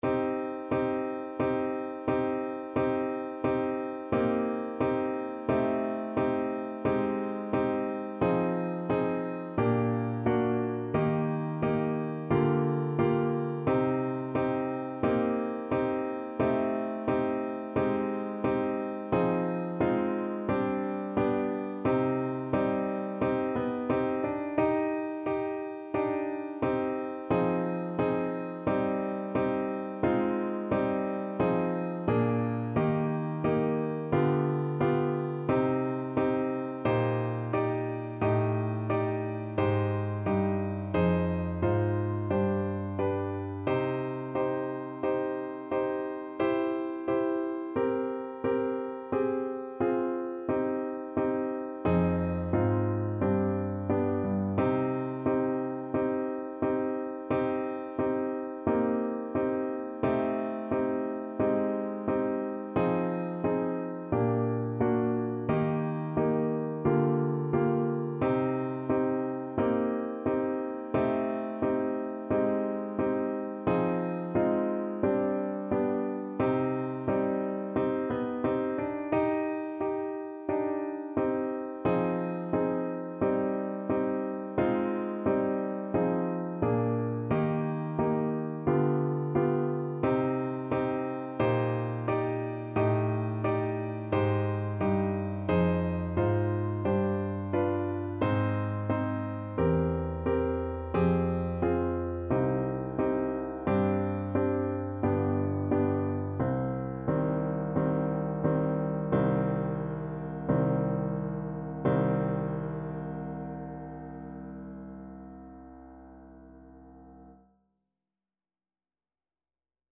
Play (or use space bar on your keyboard) Pause Music Playalong - Piano Accompaniment Playalong Band Accompaniment not yet available reset tempo print settings full screen
Andante =c.88
C minor (Sounding Pitch) G minor (French Horn in F) (View more C minor Music for French Horn )